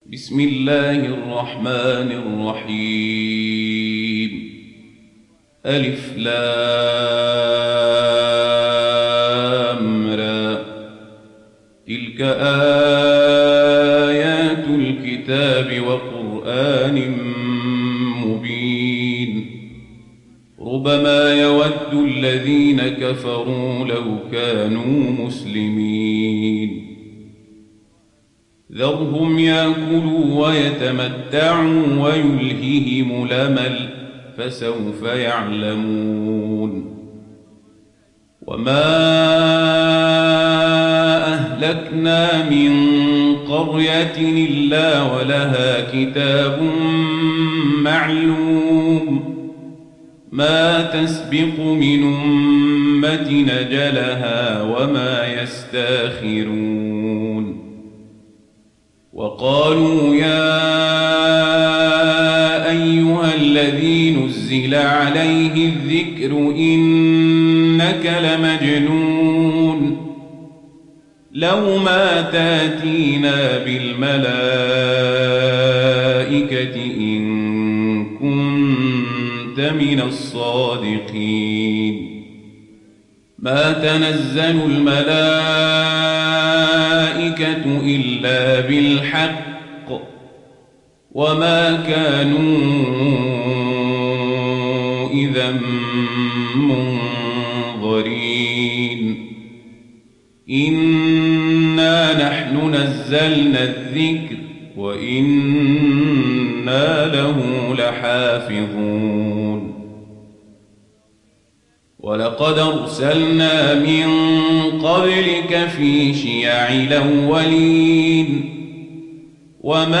Sourate Al Hijr Télécharger mp3 Omar Al Kazabri Riwayat Warch an Nafi, Téléchargez le Coran et écoutez les liens directs complets mp3